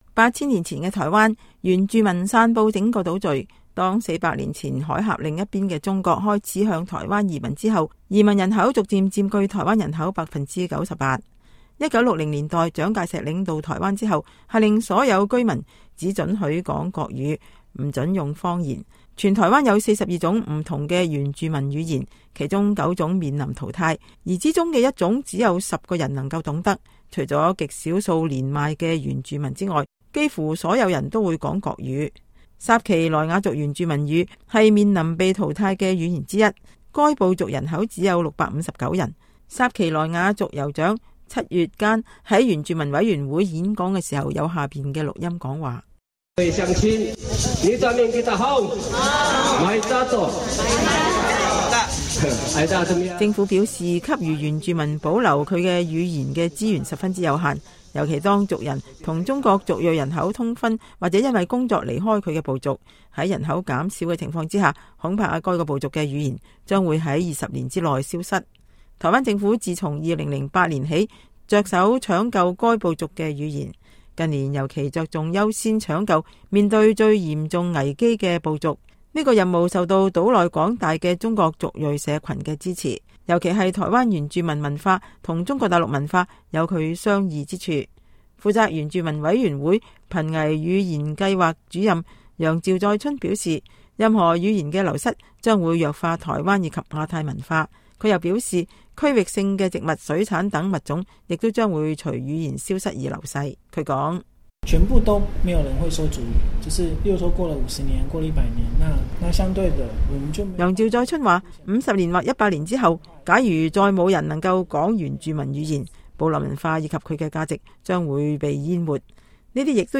薩奇來雅族酋長七月時在原住民委員會演講時﹐有下面的錄音講話。